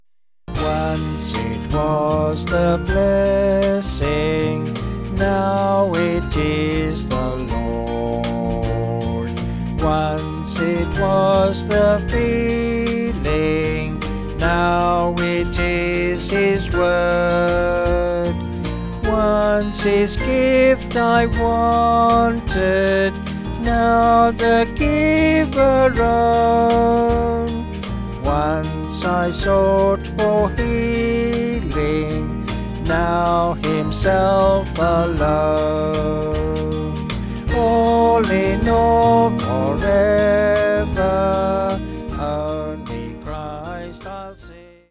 Small Band
(BH)   4/Eb-E
Vocals and Band